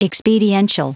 Prononciation du mot expediential en anglais (fichier audio)
Prononciation du mot : expediential